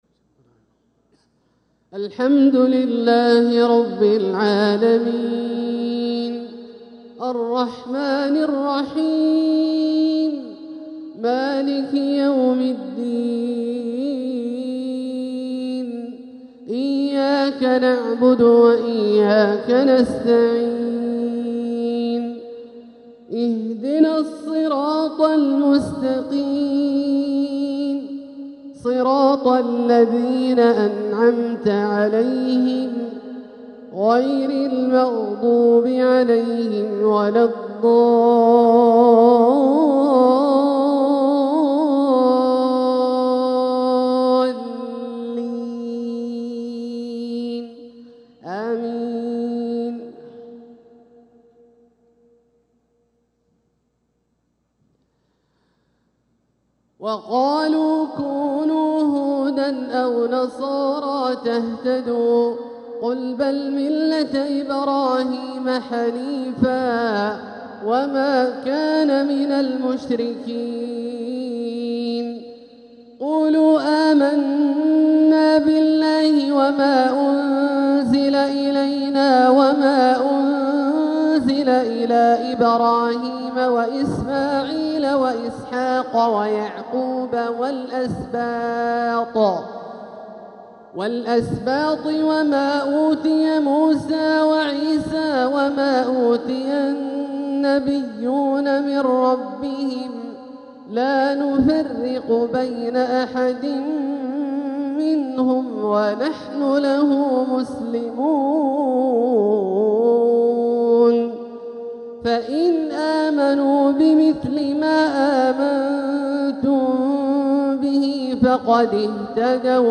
تلاوة من سورتي البقرة و آل عمران | عشاء الثلاثاء 11 صفر 1447هـ > ١٤٤٧هـ > الفروض - تلاوات عبدالله الجهني